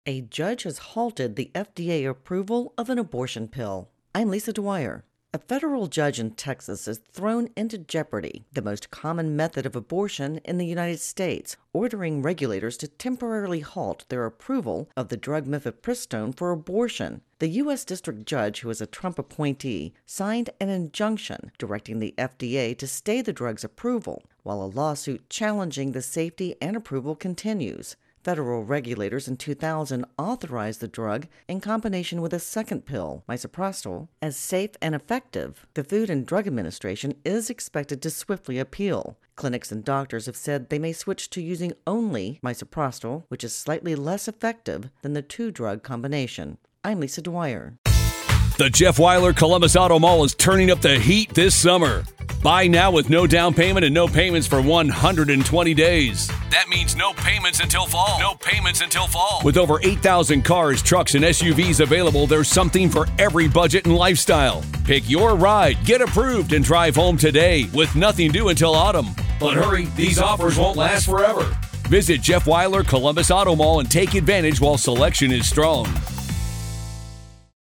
reports on Abortion Pill.